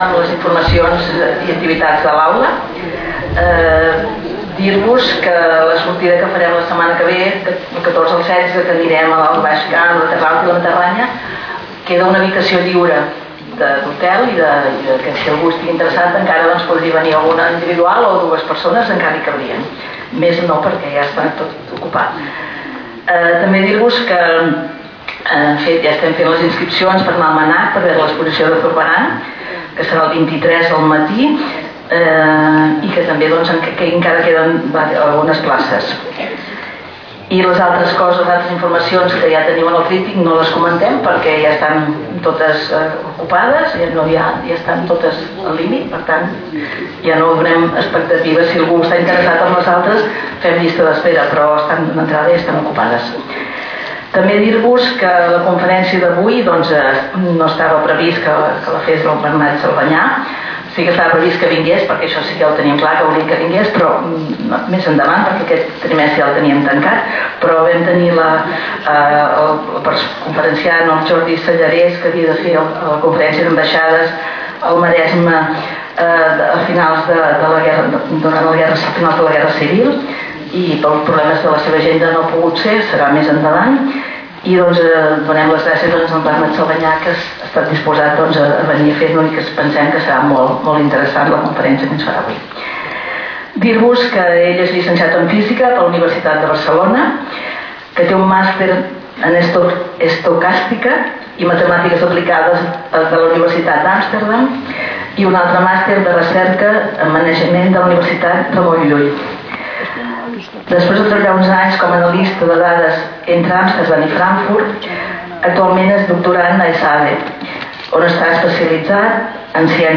Lloc: Sala d'actes del Col.legi La Presentació
Conferències